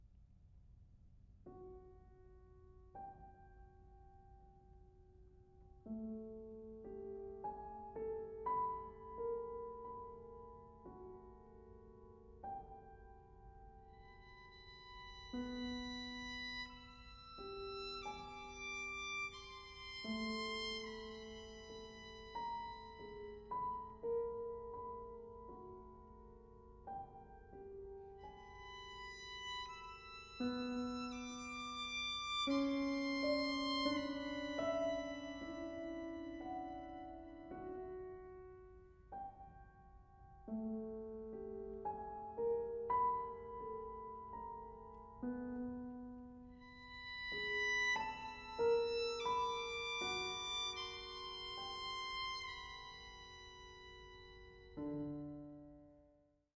violin and piano
It can at times be almost unbearably intense.